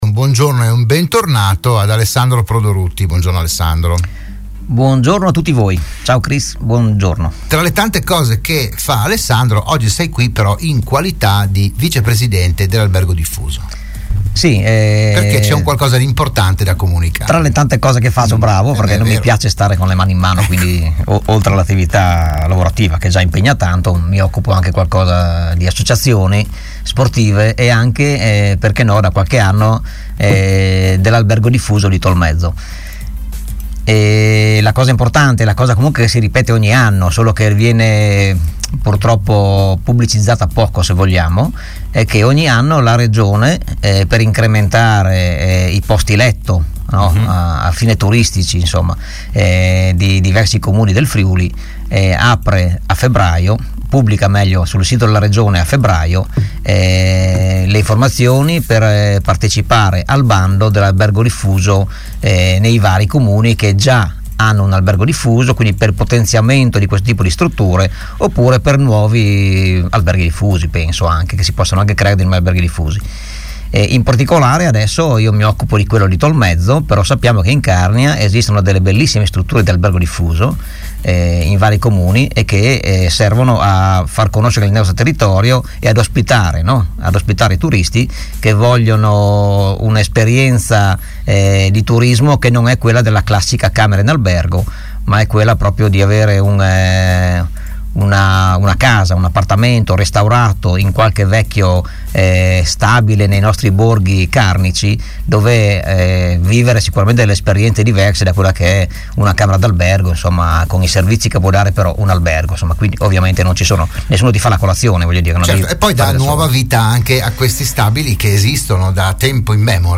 Il vicepresidente dell'Albergo diffuso di Tolmezzo, nonchè assessore comunale, ospite alla trasmissione "RadioAttiva"